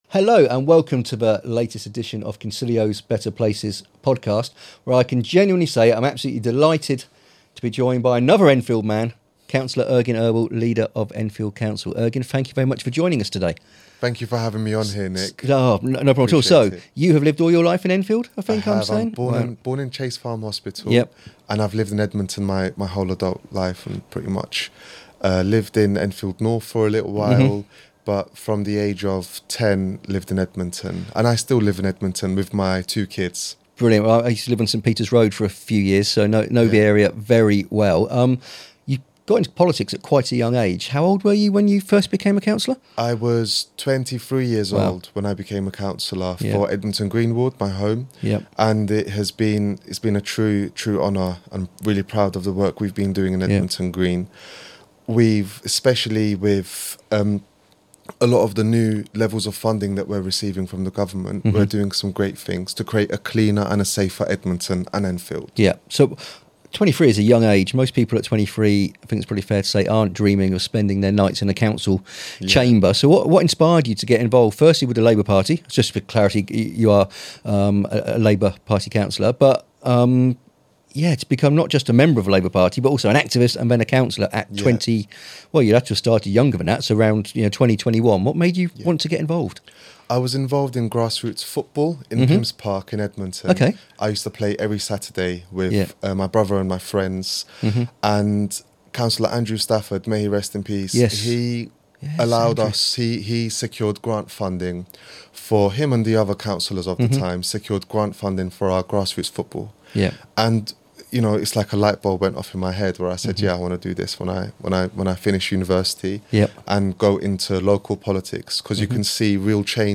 Don’t miss this conversation – listen now!